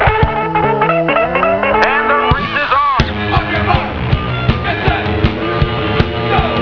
Sampling, Guitar, Backing Vocals
Vocals, Synth, Stick
Drums